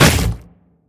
biter-roar-behemoth-8.ogg